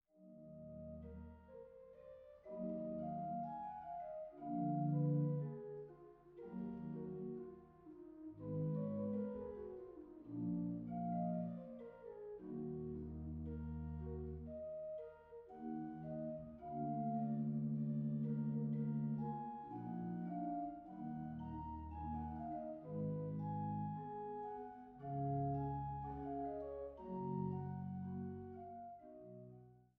Weise-Orgel in Gräfenroda